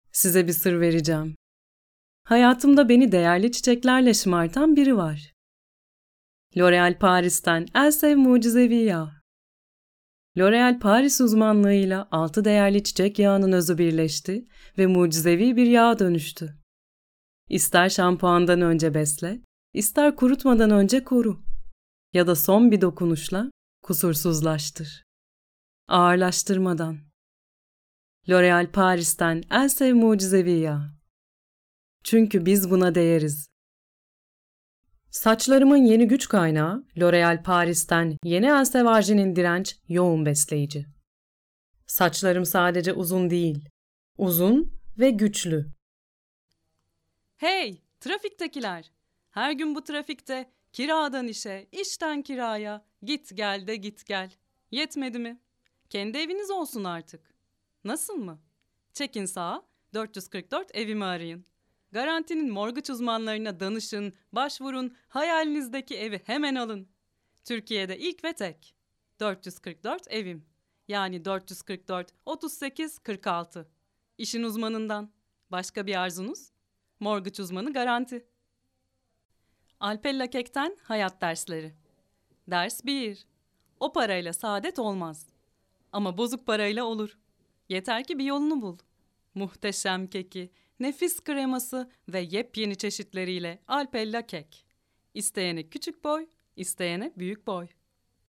Warm, deep, friendly, natural, feminine, peaceful, Turkish
Sprechprobe: Werbung (Muttersprache):